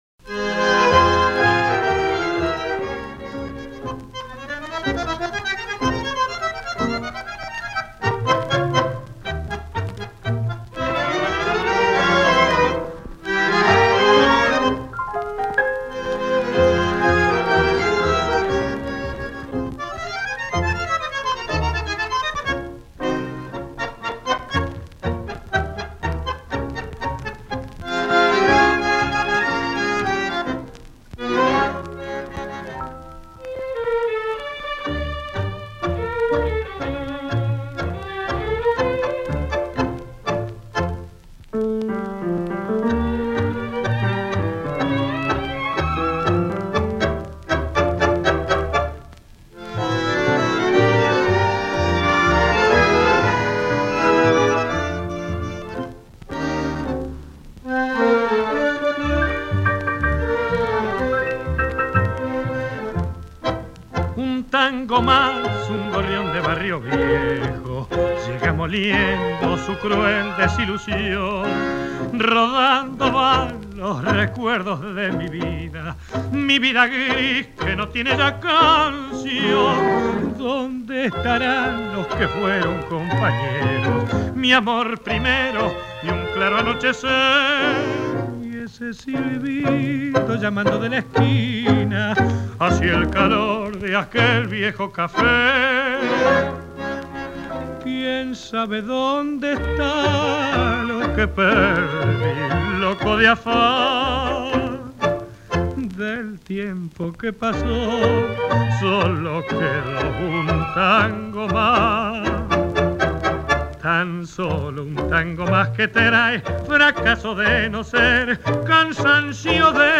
singer